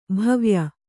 ♪ bhavya